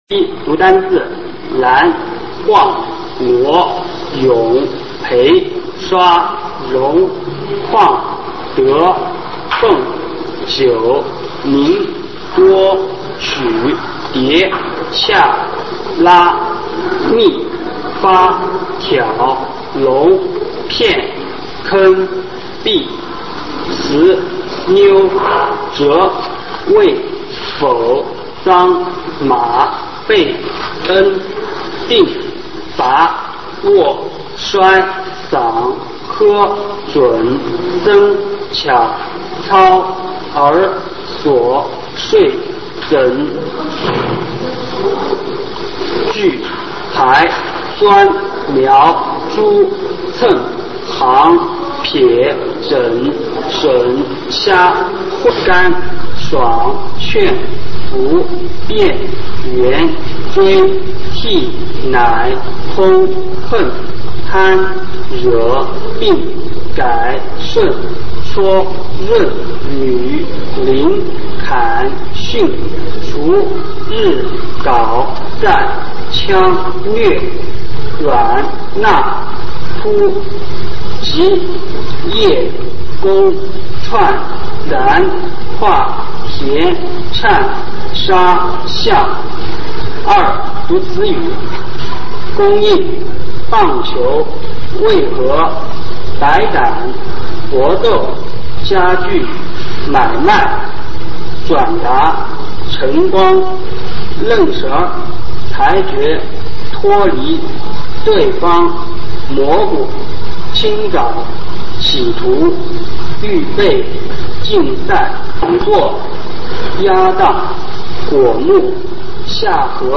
普通话水平测试二级乙等示范读音
三、朗读